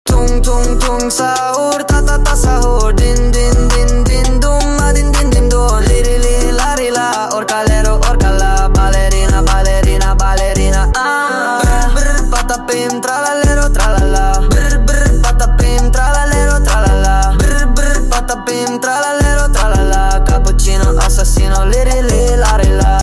dun dun dun dun dun sound effects free download